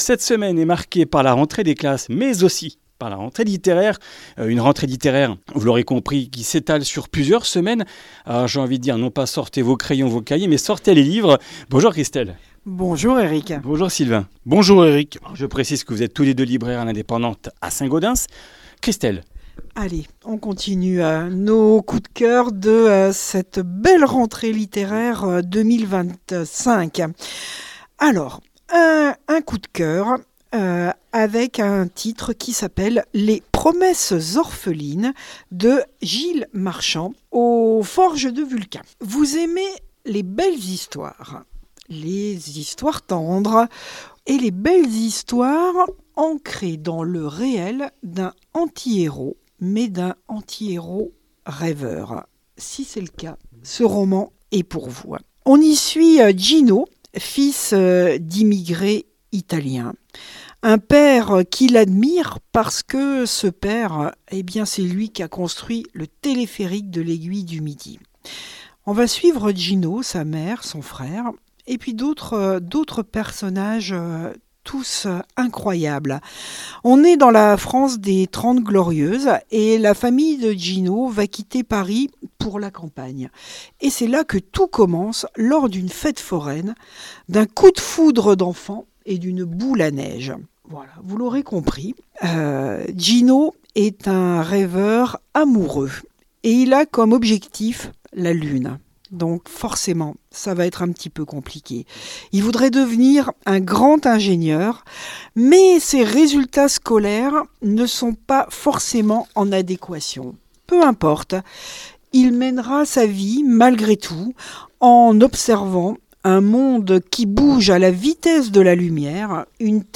Comminges Interviews du 05 sept.